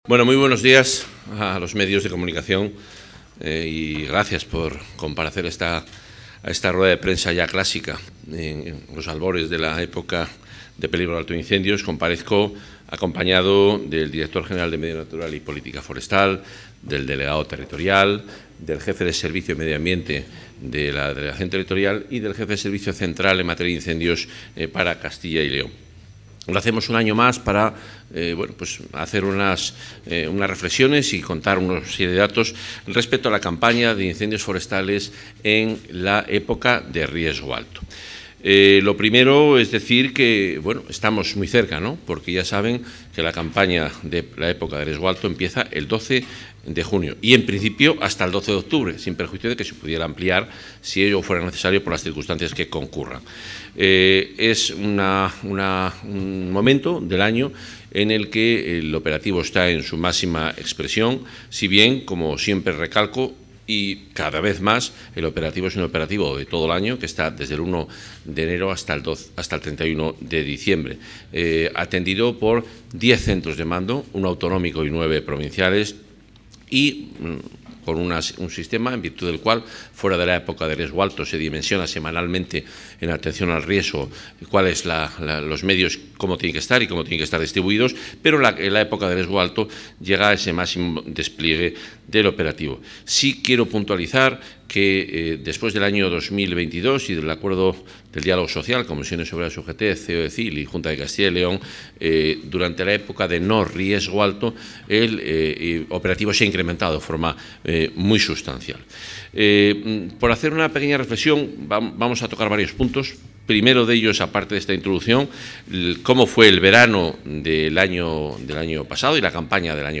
Intervención del consejero.
El consejero de Medio Ambiente, Vivienda y Ordenación del Territorio, Juan Carlos Suárez-Quiñones, ha presentado a los medios de comunicación la campaña de riesgo alto del operativo de vigilancia, prevención y extinción de incendios forestales para 2025.